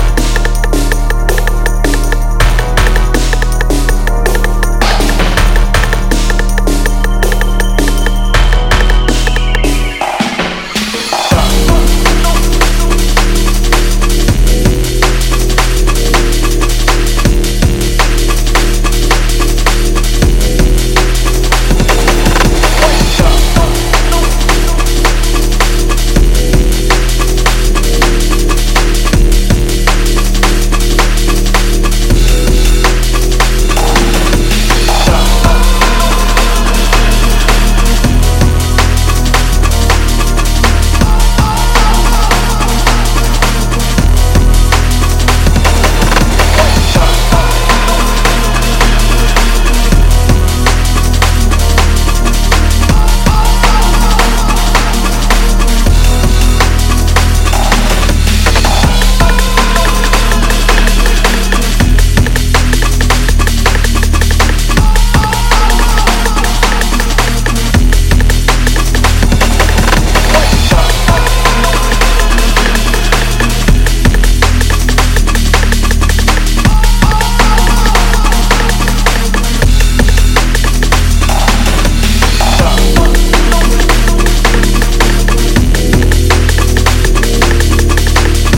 Drum & Bass / Jungle